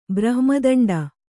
♪ brahma daṇḍa